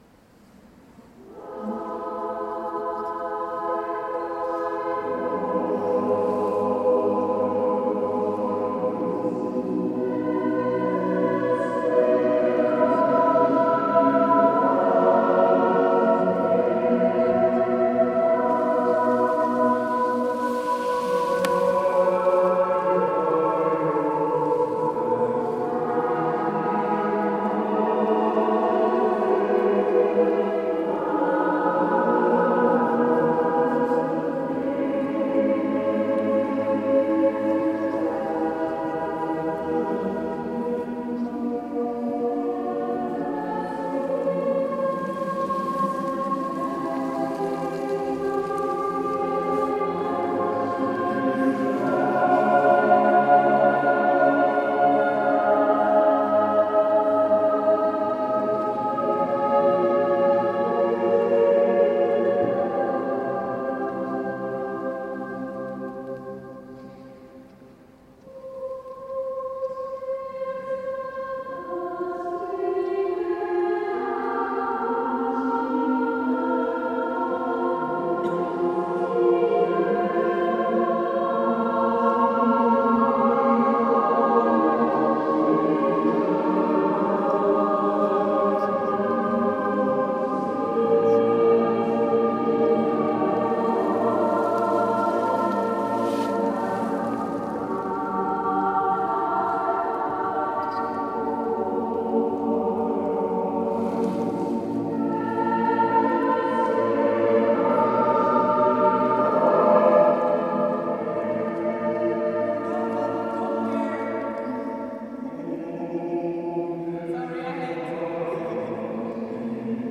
Kamerkoor De Bilt zingt voornamelijk klassieke en wereldmuziek, vier- tot acht-stemmig, a capella.
Abendlied van Josef Rheinberger (Immanuelkerk, 19 april 2015)